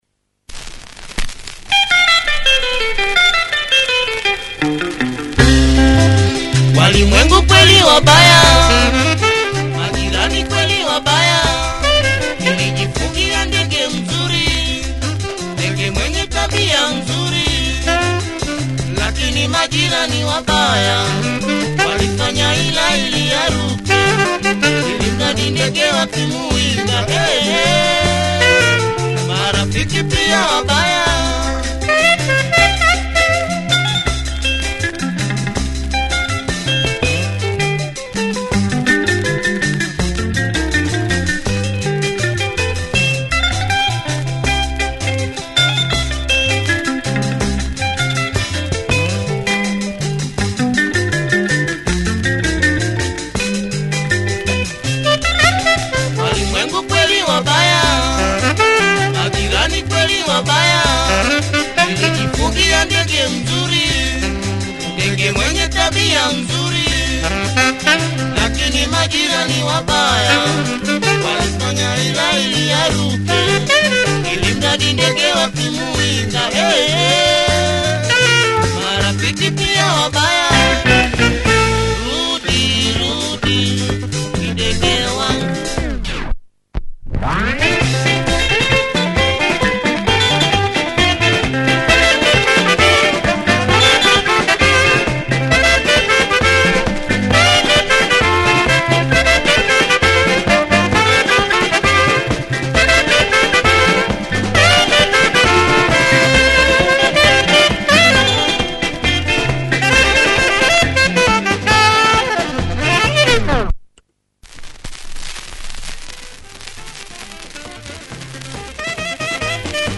Tanzania rumba. Nice sax and quality horns in the breakdown.